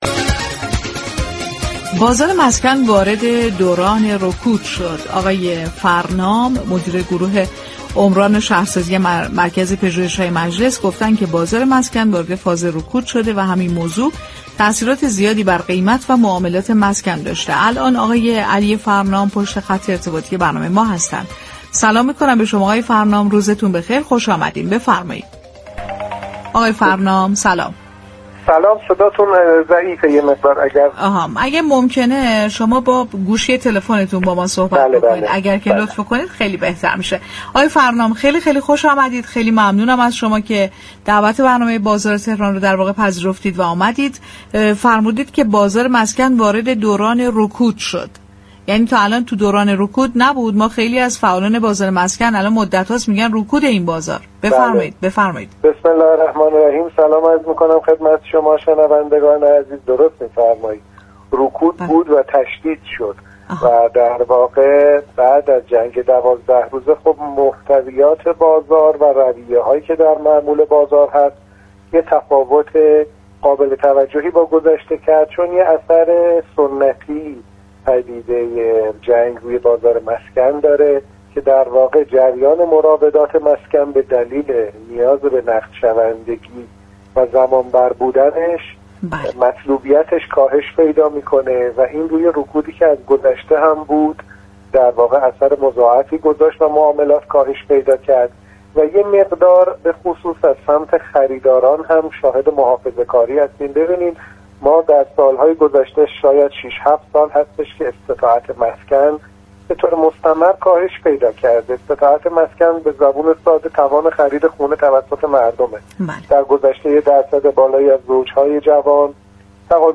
در گفتگو با برنامه «بازار تهران» رادیو تهران